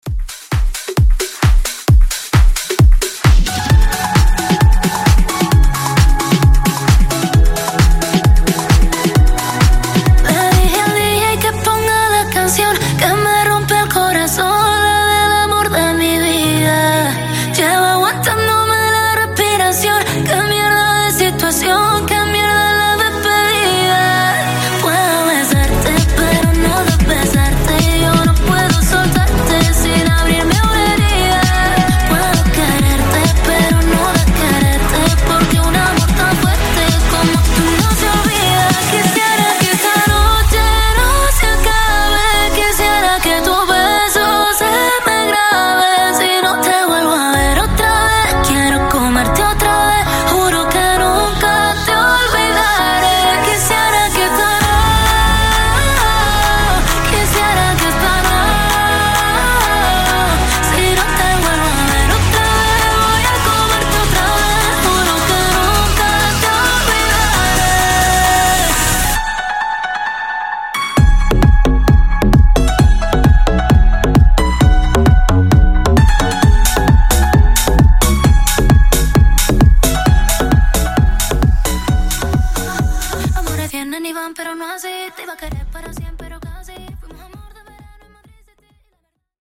Genre: 90's Version: Clean BPM: 88 Time